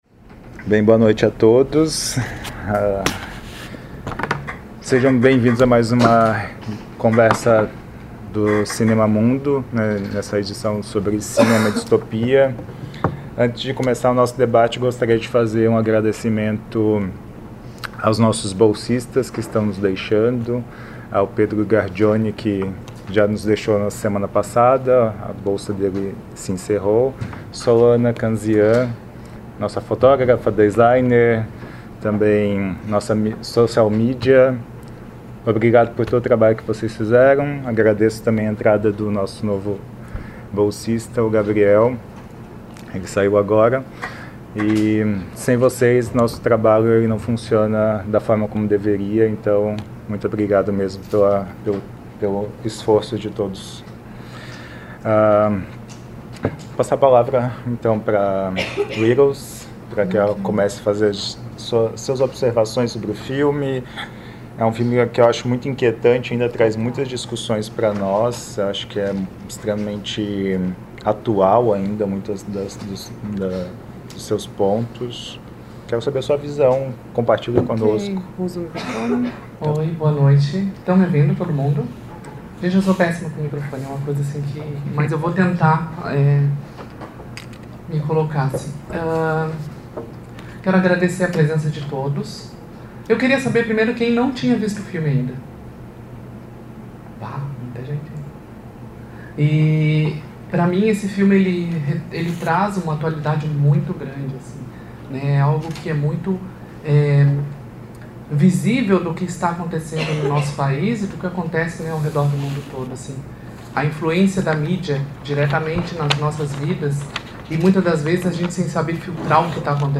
realizada em 22 de março de 2018 no Auditório "Elke Hering" da Biblioteca Central da UFSC
Debate